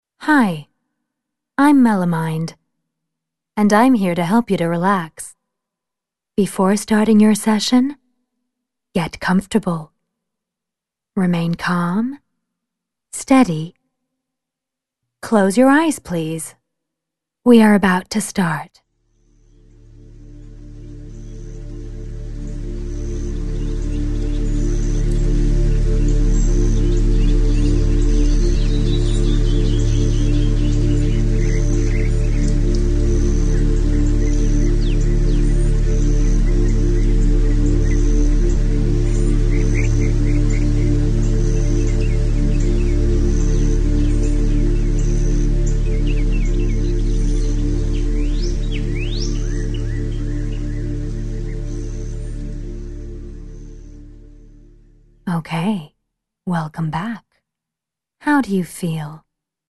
Dive into our soundscape
Let yourself be guided by the Melomind voice.
Melomind plunges you into a relaxing soundscape … listen to the birds chirping, to the sound of the waves rhythmically flooding the shore.